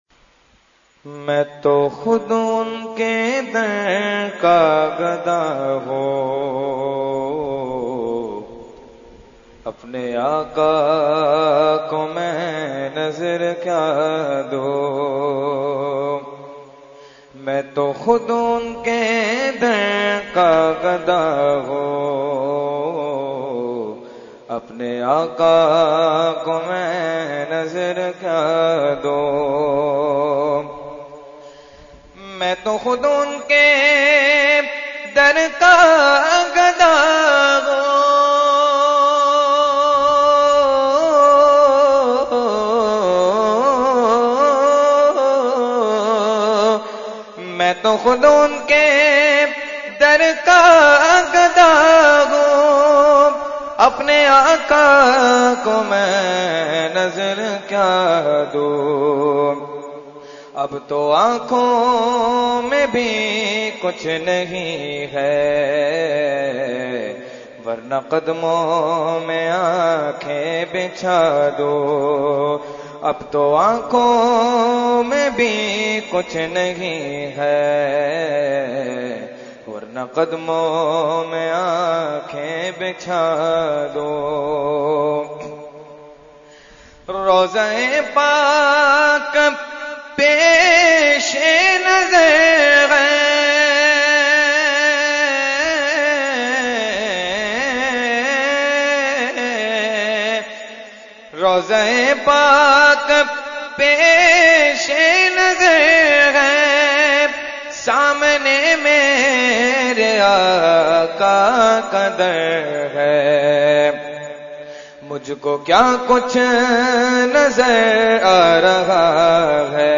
Category : Naat | Language : UrduEvent : Khatmul Quran 2018